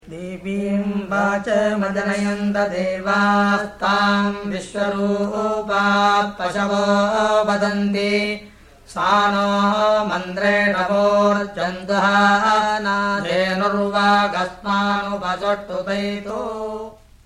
Vikrti Recitation
Samhitā
00-veda2-vkrti-samhita.mp3